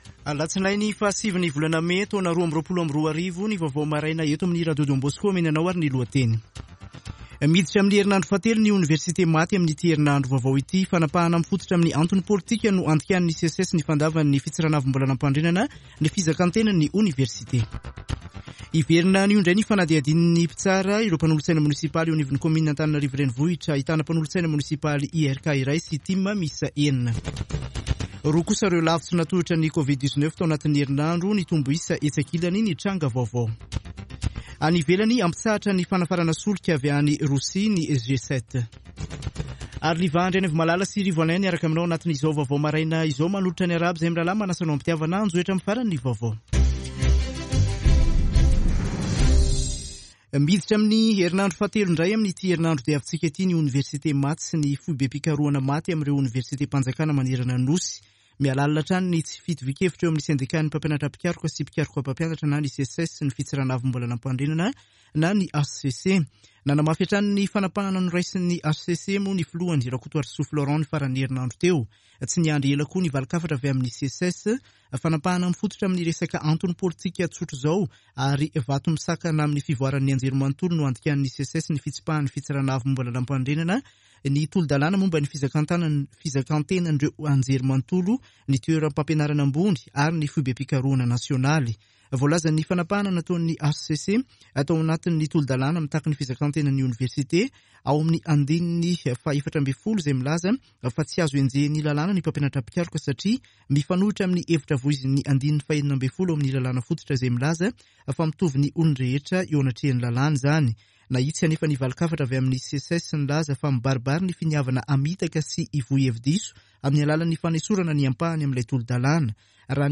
[Vaovao maraina] Alatsinainy 09 mey 2022